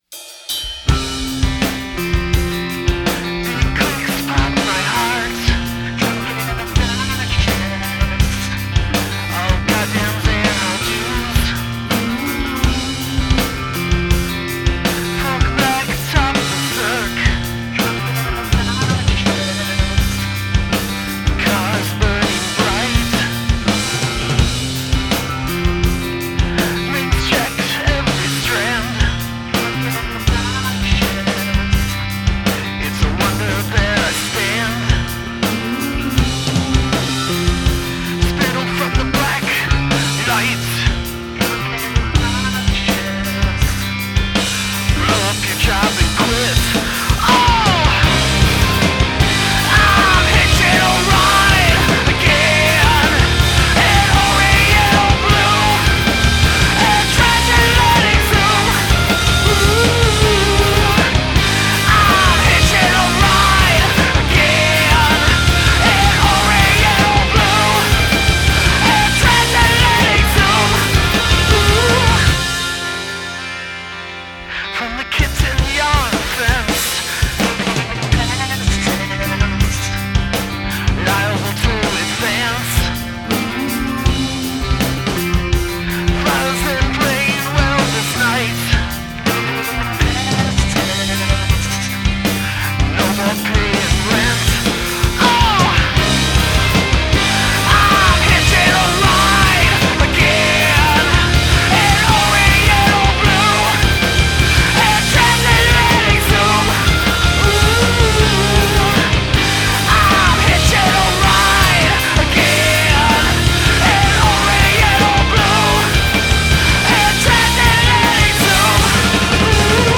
this pissed-off Indie-rock platter is mmm-good.